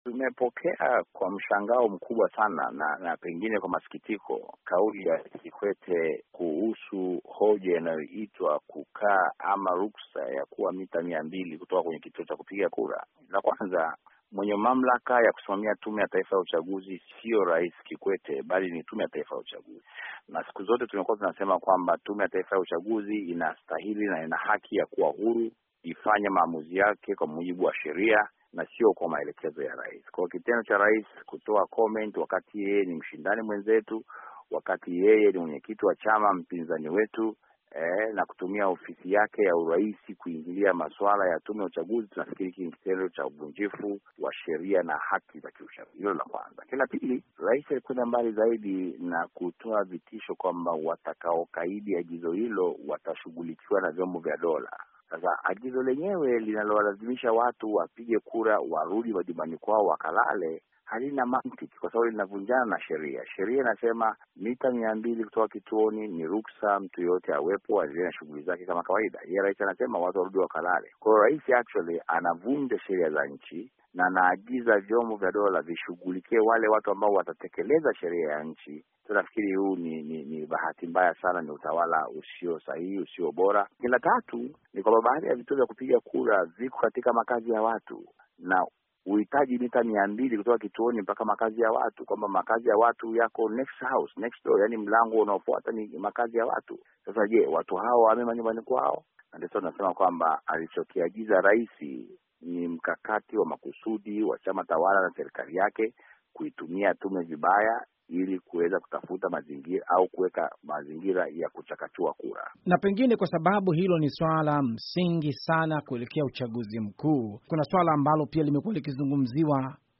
Mahojiano na Mbowe